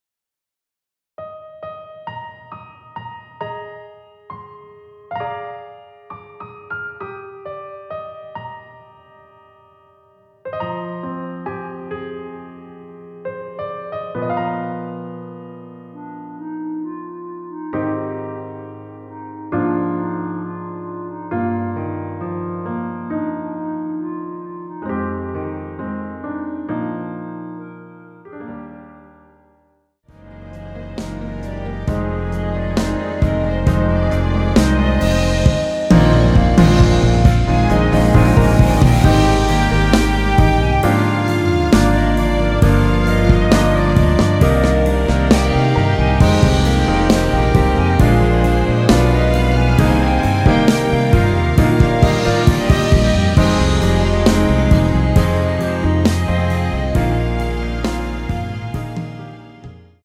원키에서(-3)내린 멜로디 포함된 MR입니다.
Eb
앞부분30초, 뒷부분30초씩 편집해서 올려 드리고 있습니다.
중간에 음이 끈어지고 다시 나오는 이유는